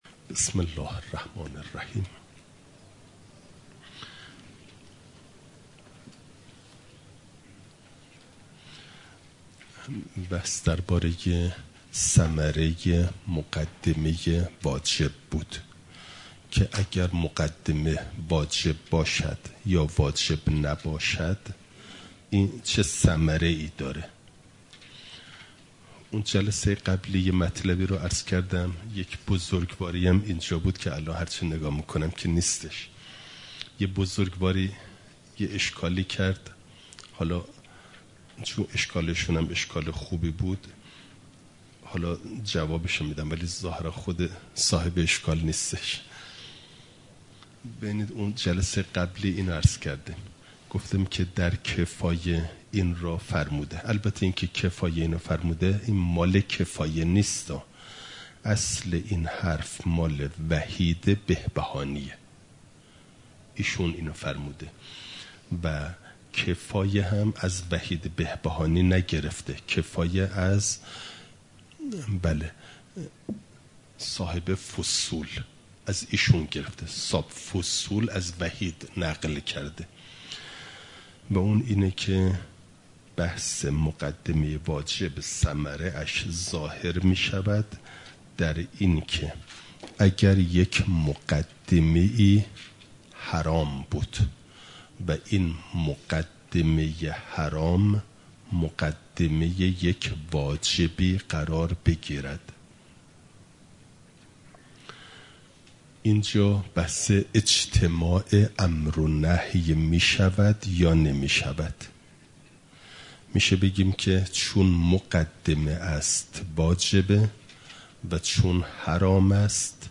خارج اصول، مقدمه واجب (جلسه ۵۴) « دروس استاد